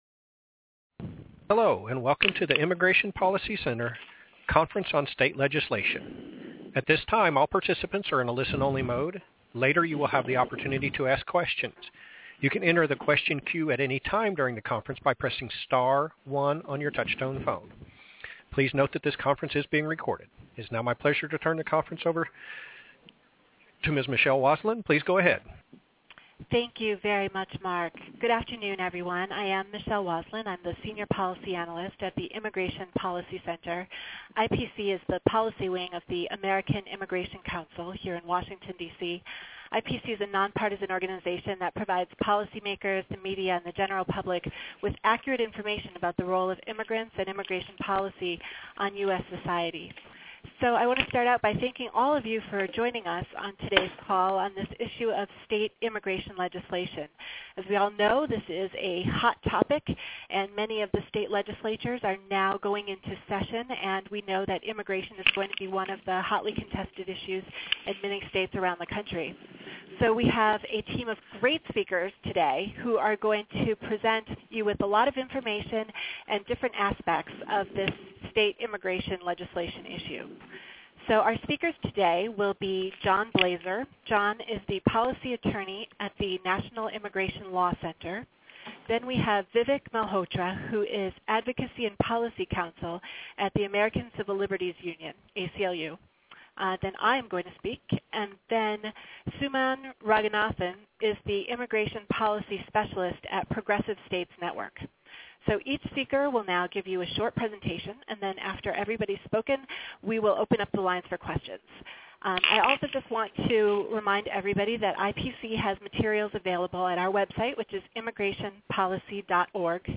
Telebriefing: Is Arizona-like "Papers Please" Legislation Coming to Your State? Listen [15] to policy experts discuss what immigration legislation is currently moving in the states, their impact on local economies, and current and potential legal challenges to these laws here.